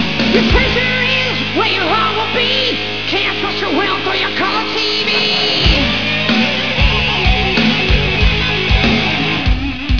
Solo  de guitarra